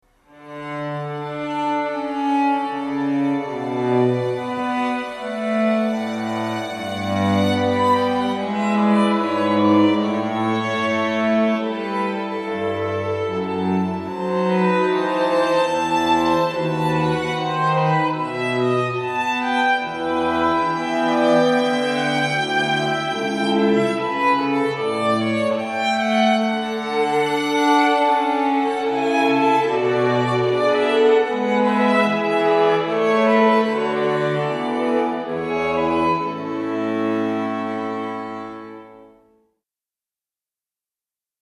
Festliche Streicherklänge zur Zeremonie
A Streichquartett (unsere Grundbesetzung: 2 Violinen, 1 Viola, 1 Violoncello)
(Besetzung A: Streichquartett)